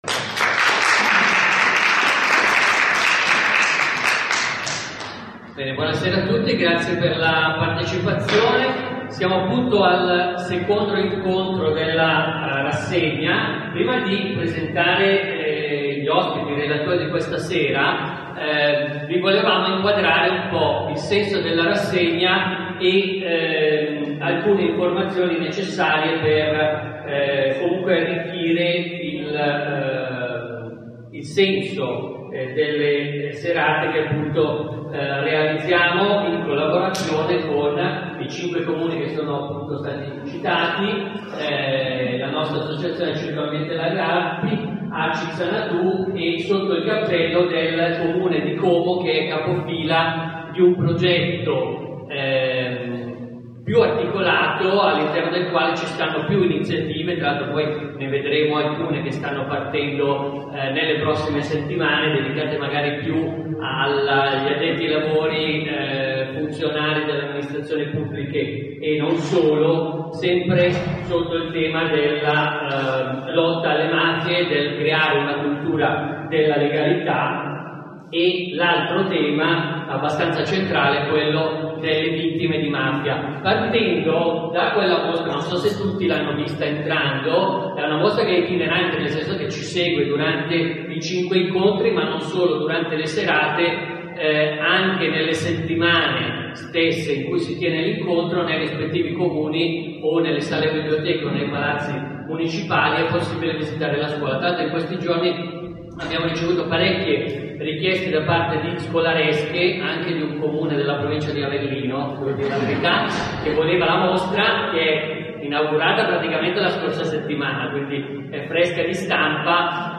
Carugo, ore 21:00 Biblioteca Comunale